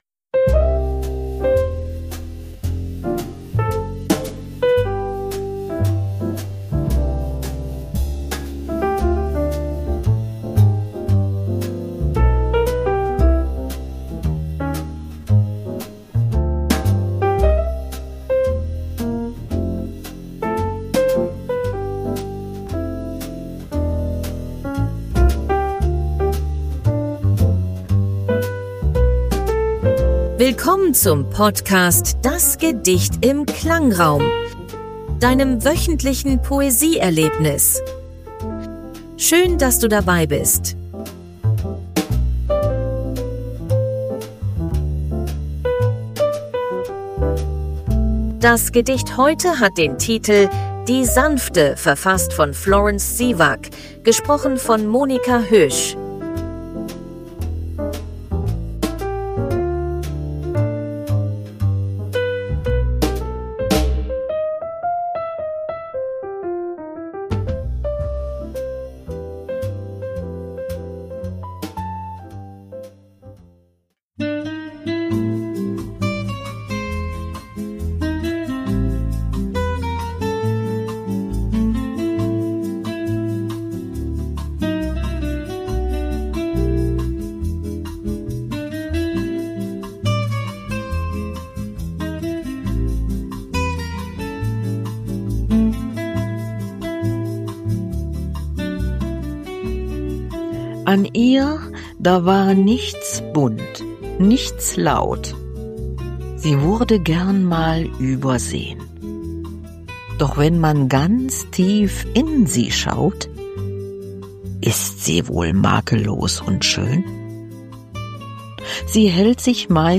Die Hintergrundmusik wurde